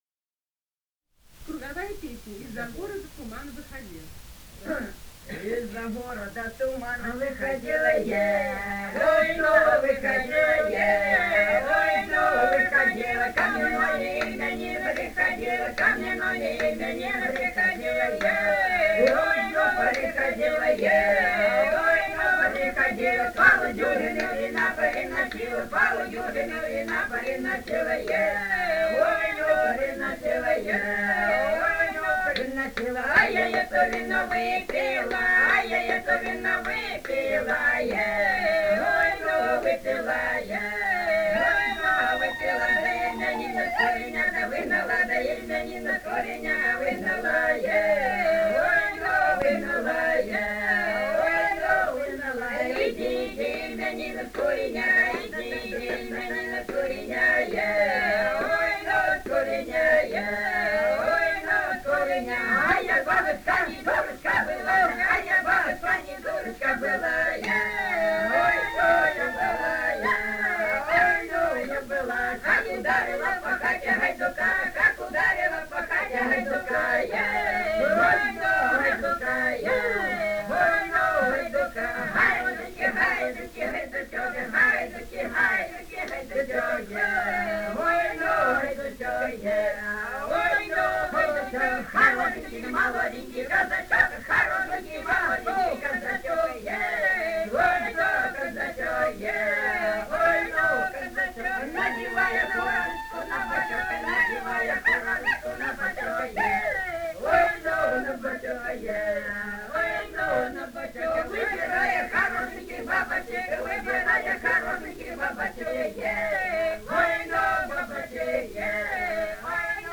полевые материалы
Ростовская область, г. Белая Калитва, 1966 г. И0942-02